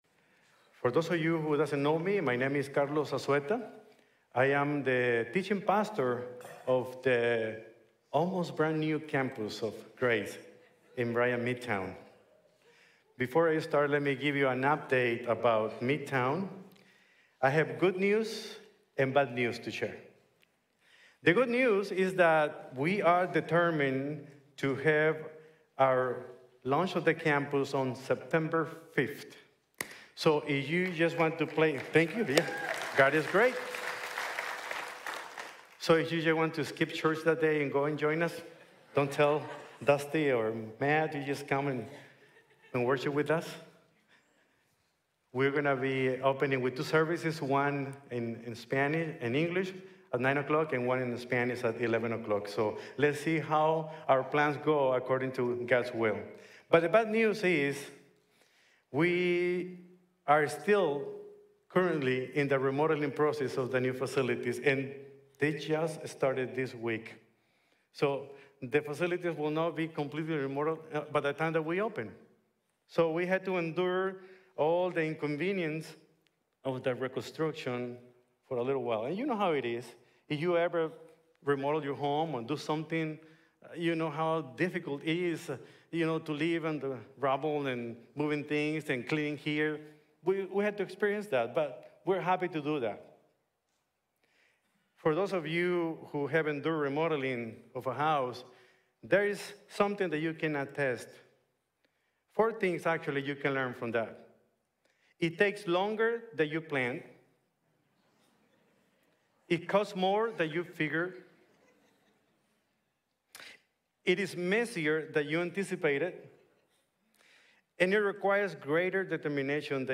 Nehemiah: It’s Time to Rebuild Worship | Sermon | Grace Bible Church